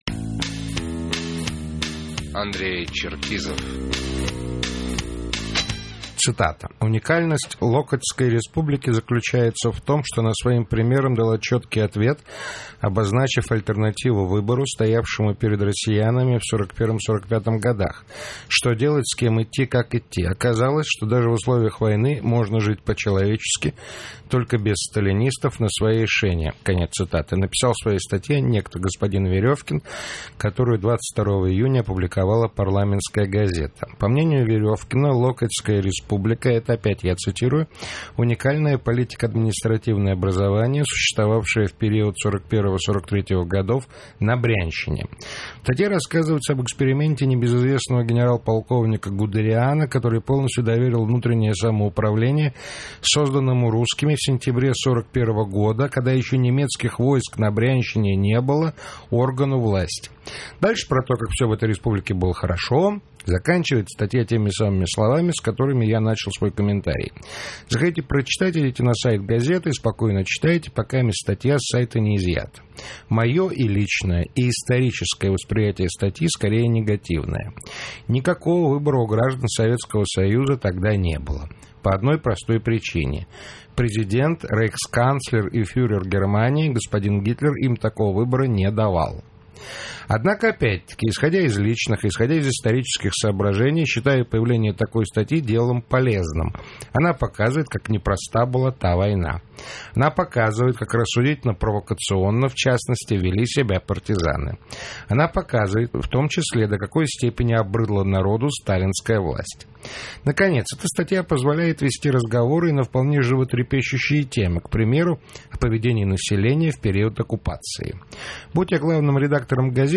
Комментарий политического обозревателя радиостанции "Эхо Москвы" Андрея Черкизова